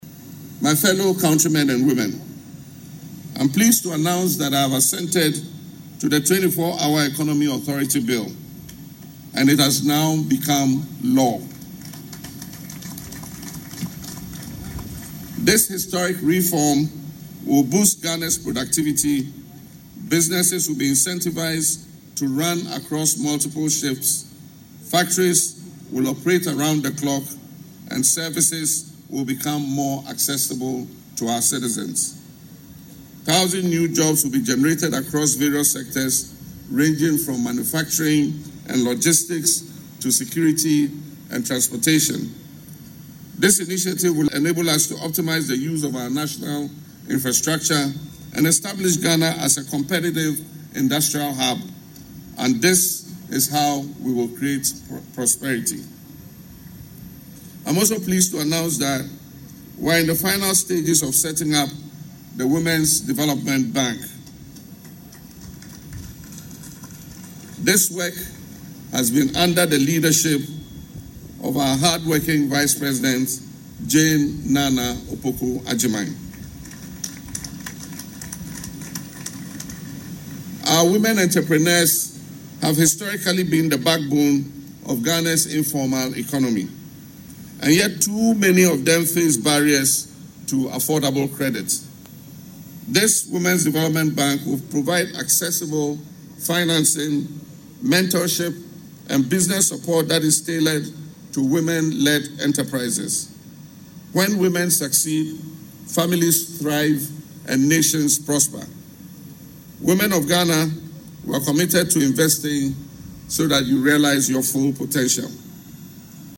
In his Independence Day address, the President said the initiative is aimed at addressing the long-standing challenges women face in accessing affordable credit for business development.
LISTEN TO PRESIDENT MAHAMA IN THE AUDIO BELOW: